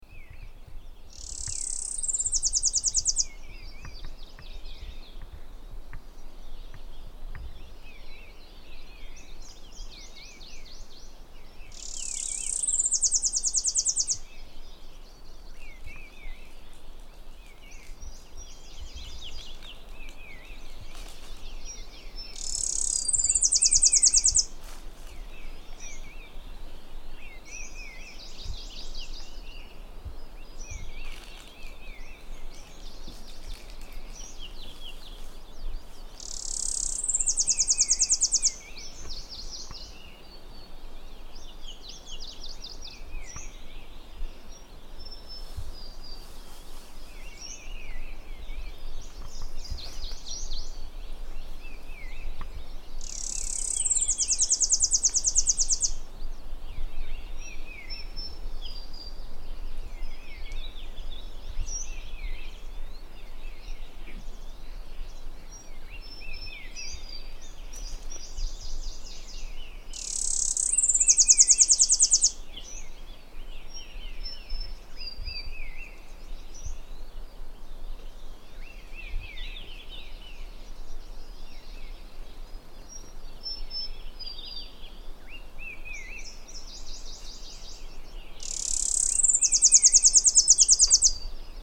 Sichuan Leaf Warbler Phylloscopus forresti
The song is a series of rapid high pitched trills, each ending with a Eurasian Wren-like rattle: